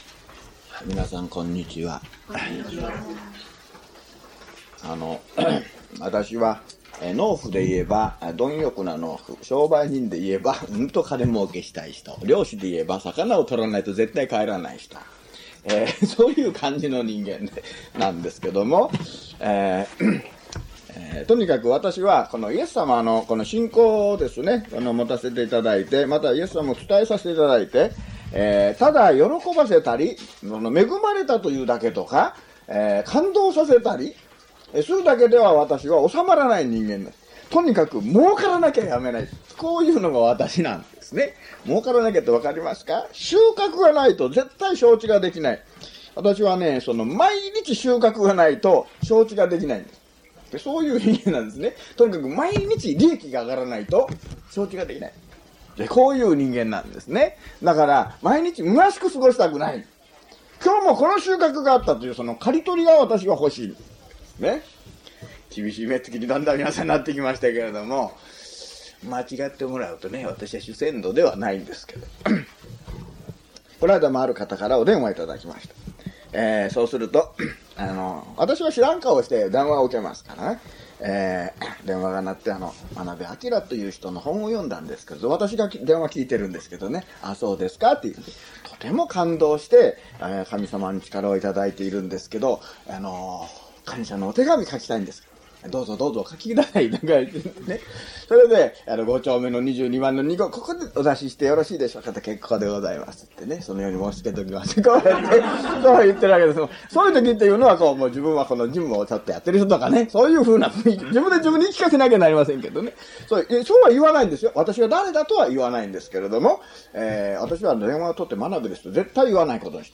luke004mono.mp3